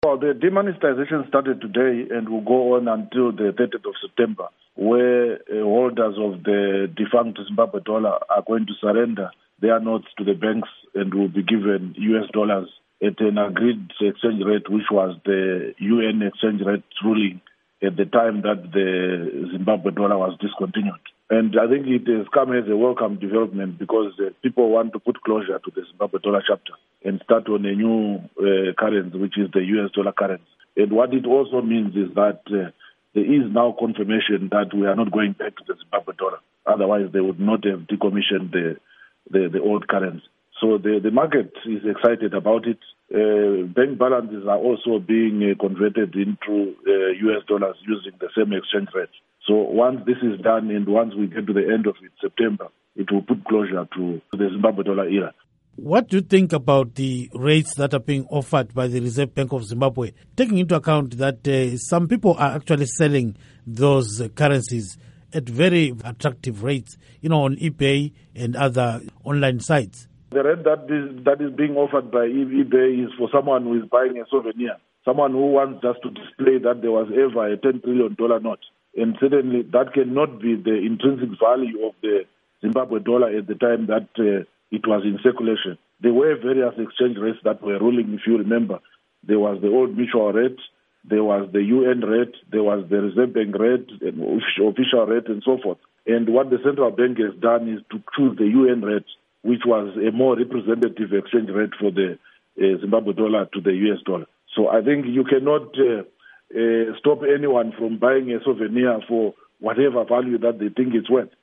Interview With John Mushayavanhu on Demonitization